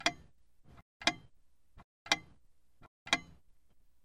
Antique clock ticking sound - Eğitim Materyalleri - Slaytyerim Slaytlar